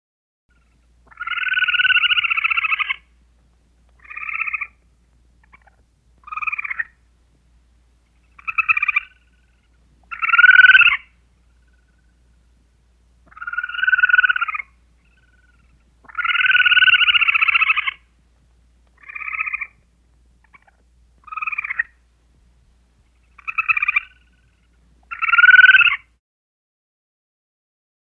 Звуки бурундуков
Звук самки бурундука